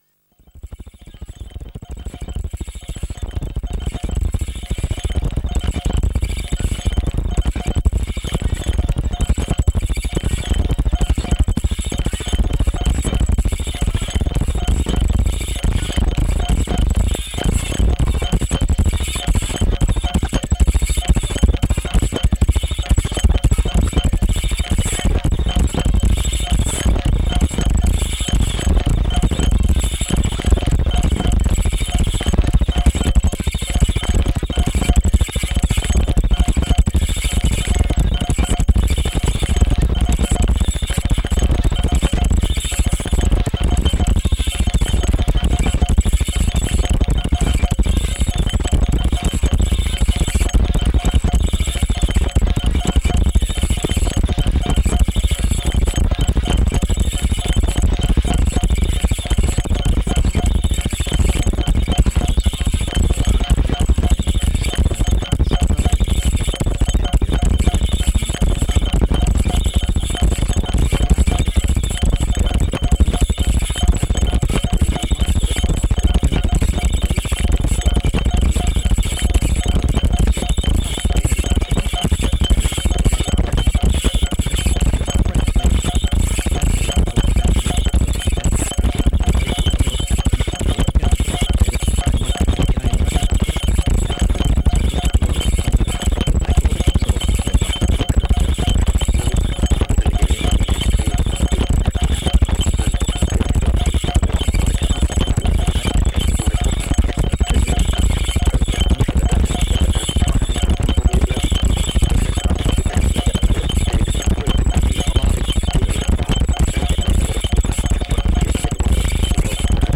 original mix (1990).